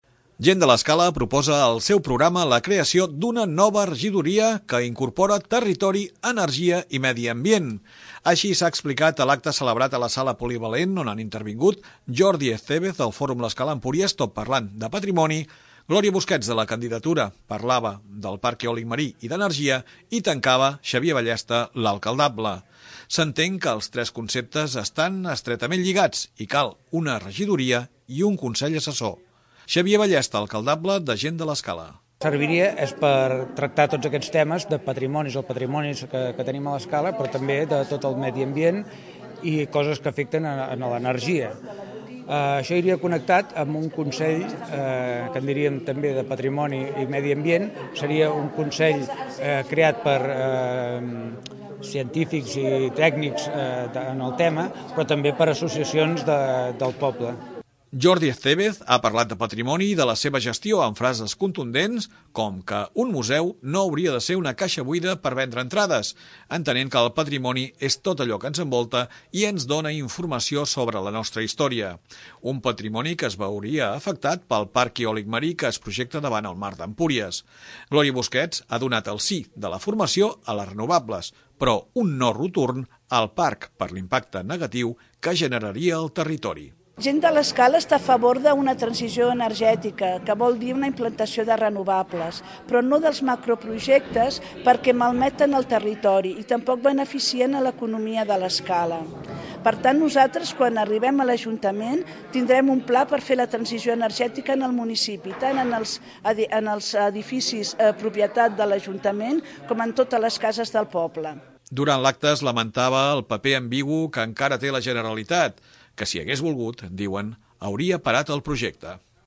Gent de l'Escala aposta per la creació d'una nova regidoria que englobi Territori, Energia i Medi Ambient i un consell assessor format per tècnics i ciutadania. En una xerrada a la sala Polivalent s'ha donat un no contundent al Parc Eòlic Marí.